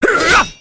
Barbarian Attack Sound Effect Free Download